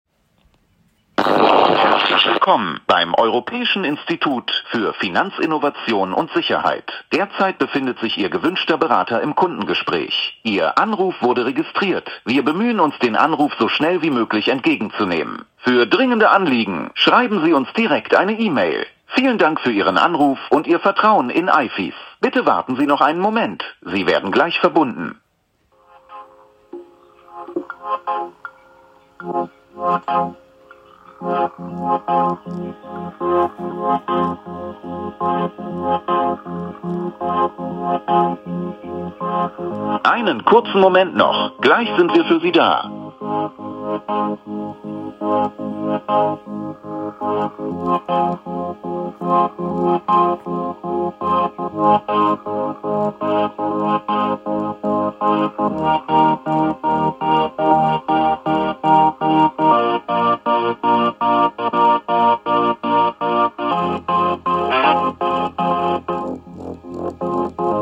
Nach 9 Uhr hatten wir eine Warteschleifenansage, die wir nach einer Stunde beendet haben.
(Audiomitschnitt 21.05.2025, 9:15 Uhr)